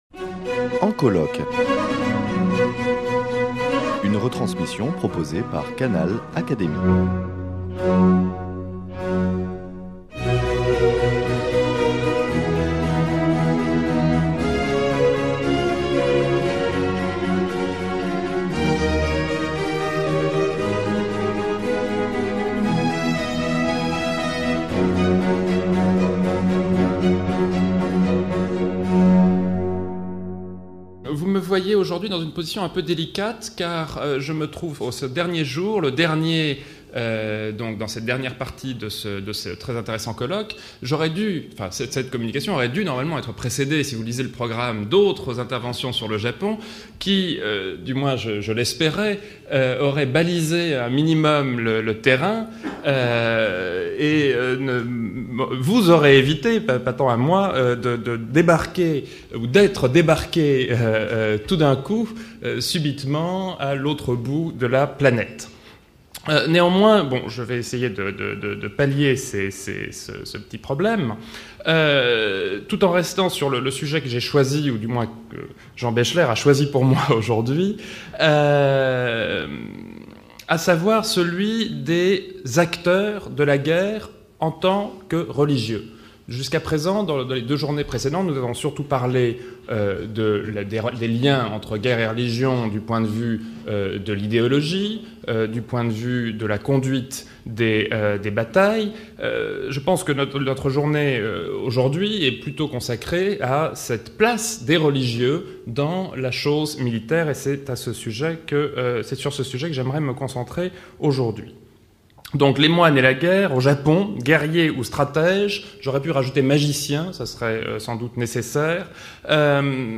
lors du colloque « Guerre et société », à la Fondation Simone et Cino del Duca.